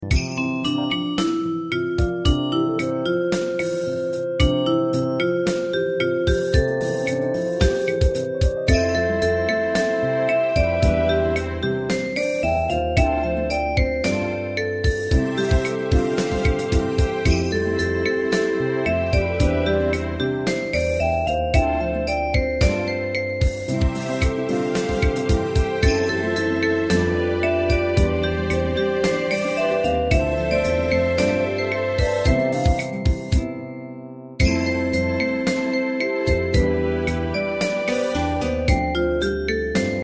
大正琴の「楽譜、練習用の音」データのセットをダウンロードで『すぐに』お届け！
カテゴリー: ユニゾン（一斉奏） .
日本のポピュラー